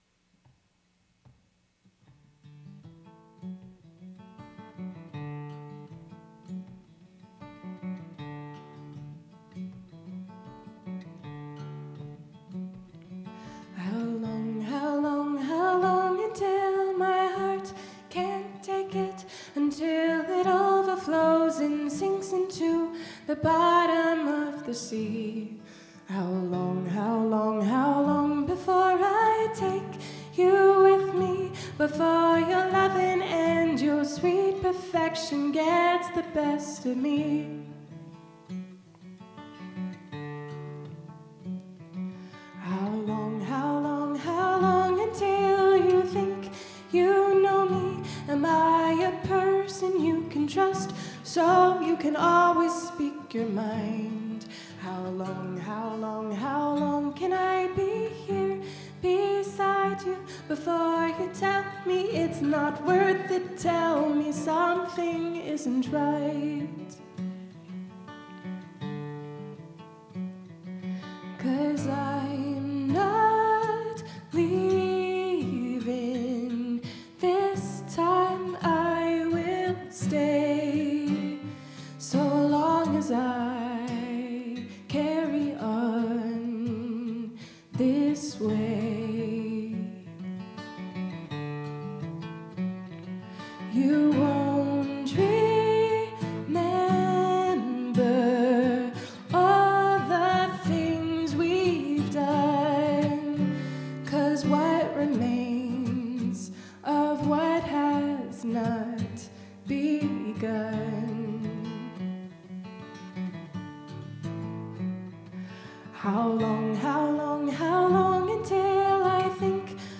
Live recording.
vocals
guitar